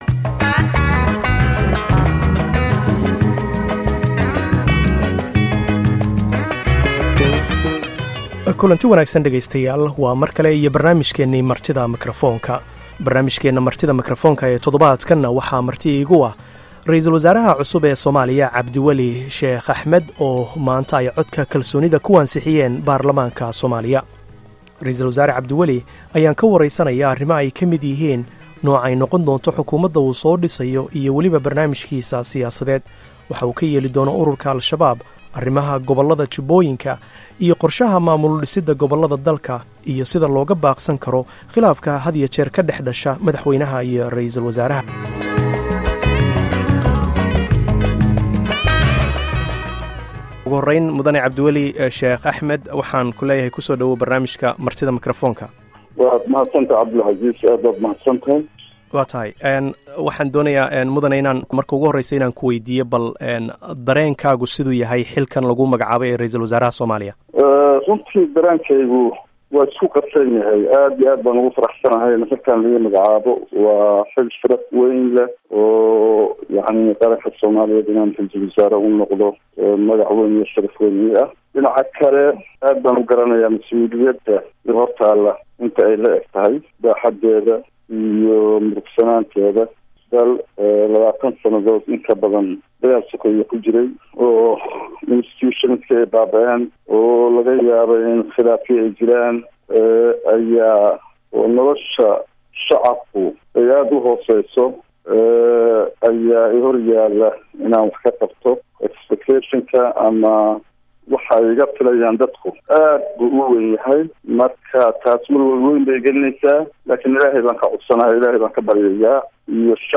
Barnaamijka Martida Makfaroonka waxaa toddobaadkan marti ku ah Ra'iisul-wasaaraha cusub ee Soomaaliya Cabdiweli Sheekh Axmed oo wareysi khaas ah siiyay idaacadda VOA waxyar kadib markii ay ansixiyeen baarlamaanka Soomaaliya.